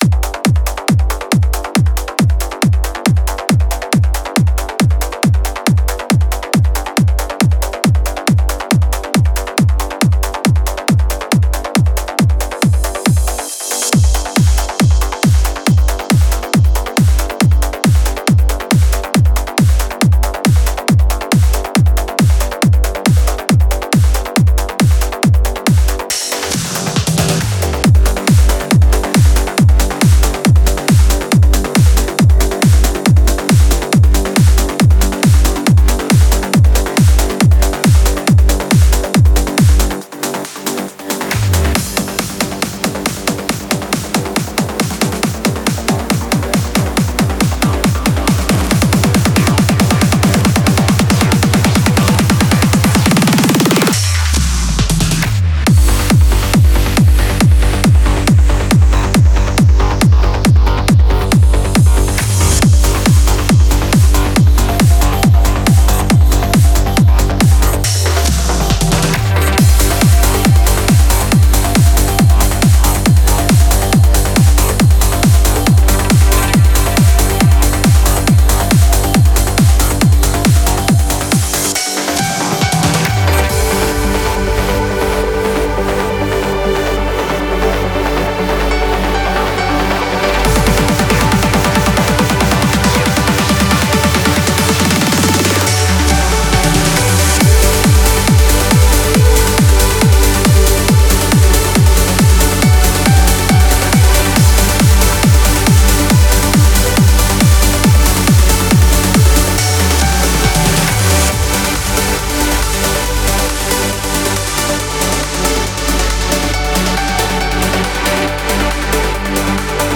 Назад в ¤Trance / Vocal Trance / Progressive Trance ¤
Стиль: Trance